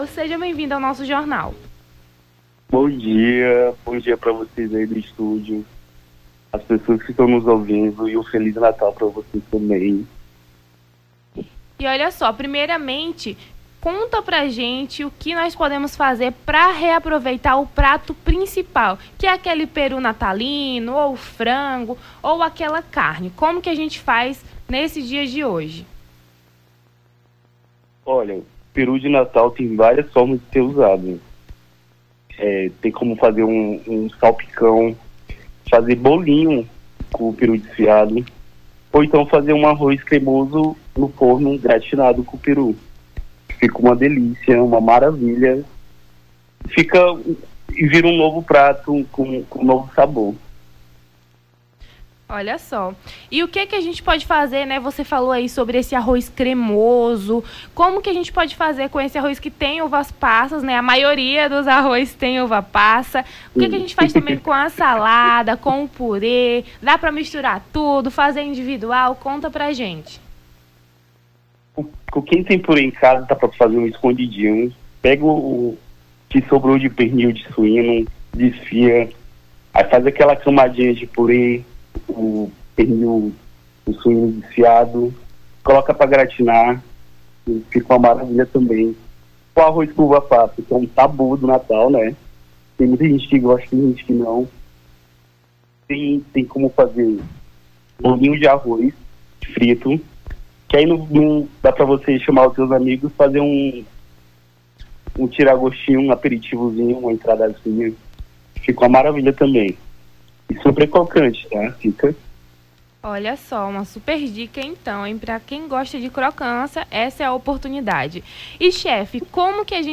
Nome do Artista - CENSURA - ENTREVISTA (REAPROVEITAR CEIA NATAL) 25-12-23.mp3